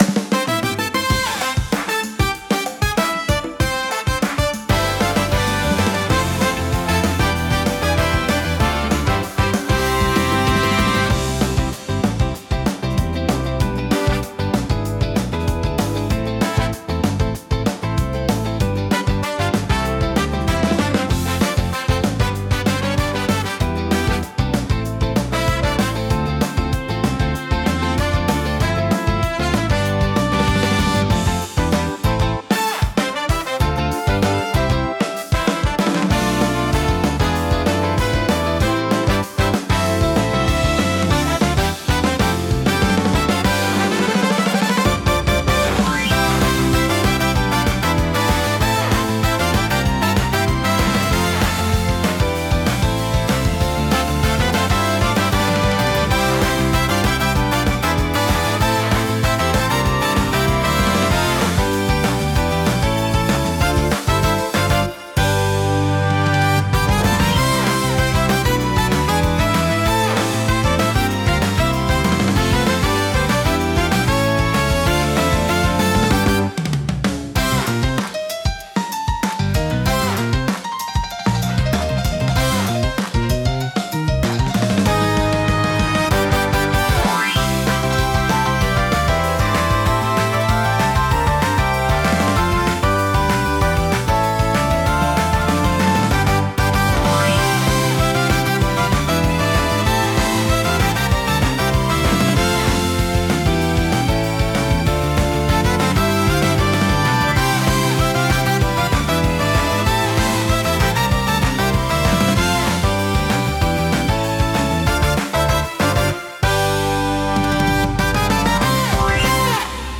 明るくハッピーな曲調が特徴のジャンルです。
軽快なリズムとポップなメロディが楽しい雰囲気を作り出し、買い物やショッピング体験を爽やかに演出します。